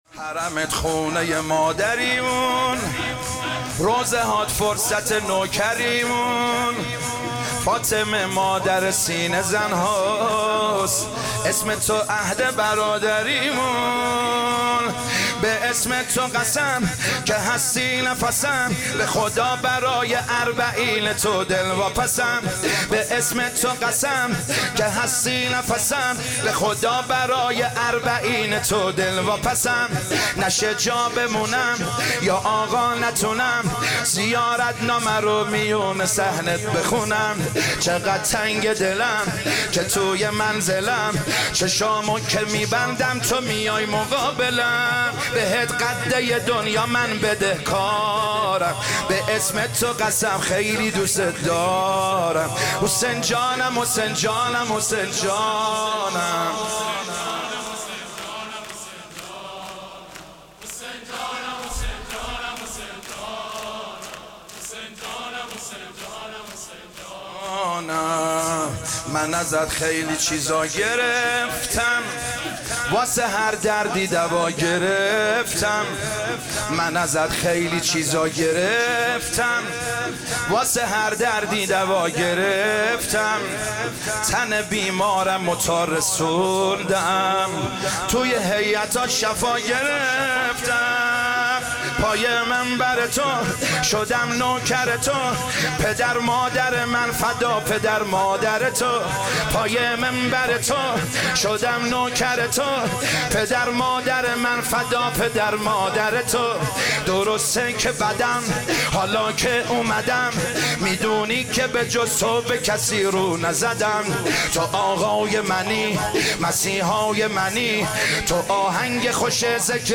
حرمت خونه مادریمون شور
شب ششم محرم 1399